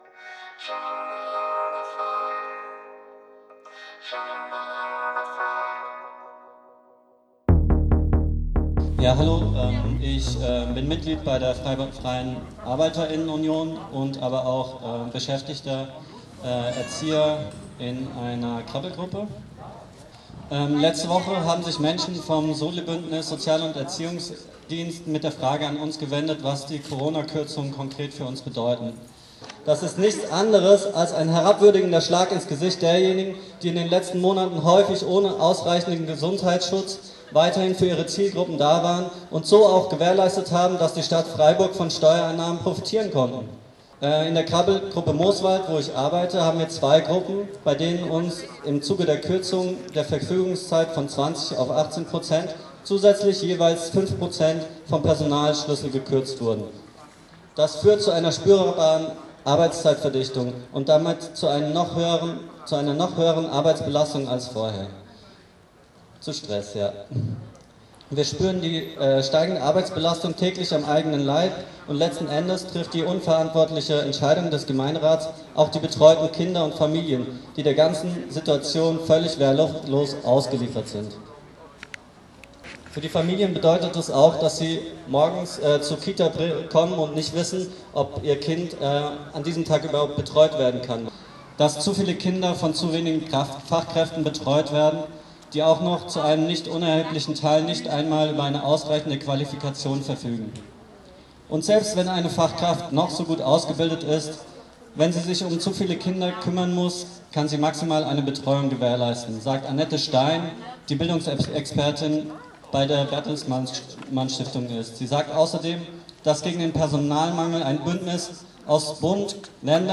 Kundgebung vor der Gemeinderatssitzung : "Schlag ins Gesicht" - Stadt wertet Soziale- und Erziehungsarbeit weiterhin ab